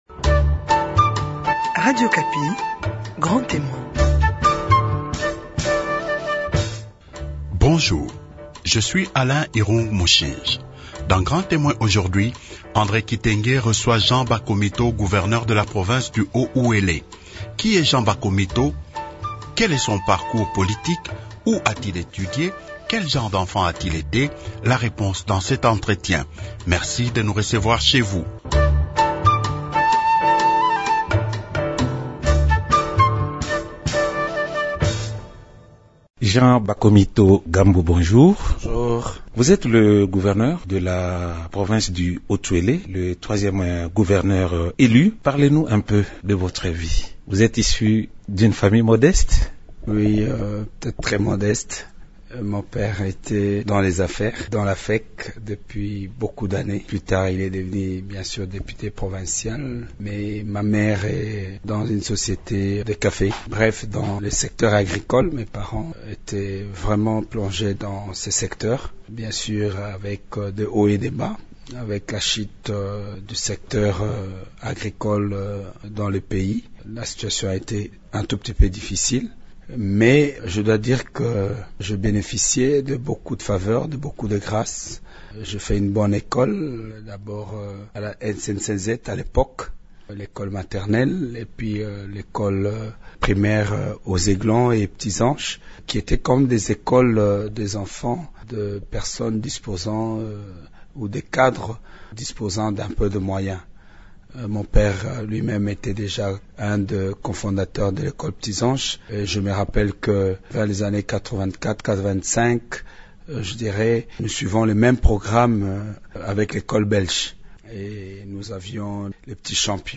Grand Témoin reçoit Jean Bakomito, gouverneur de la province du Haut Uele.